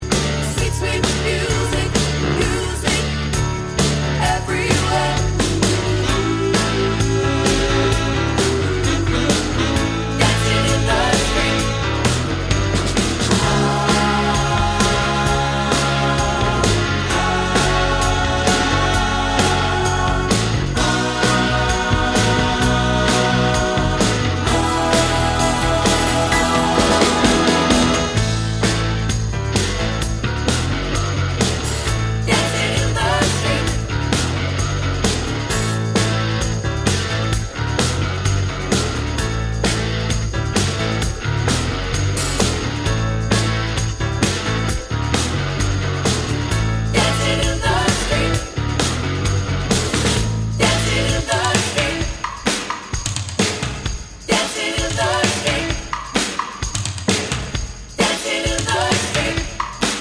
Down 2 Semitones Duets 3:12 Buy £1.50